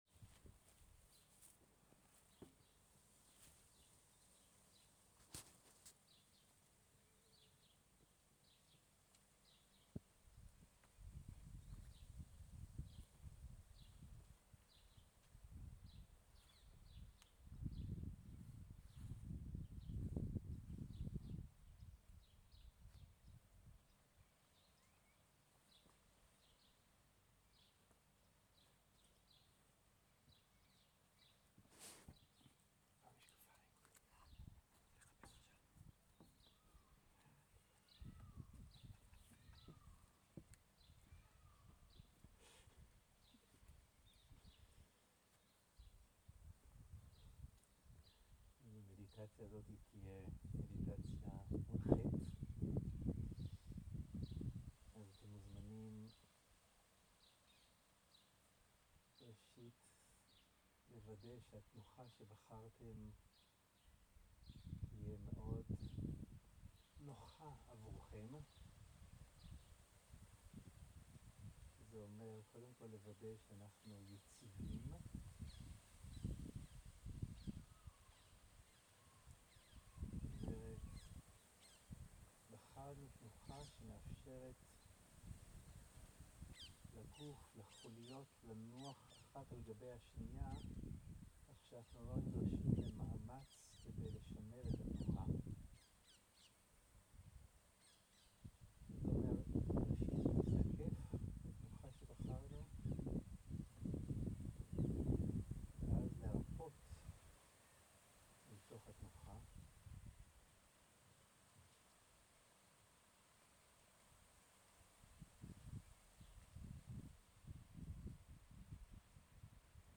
מדיטציה מונחית
איכות ההקלטה: איכות גבוהה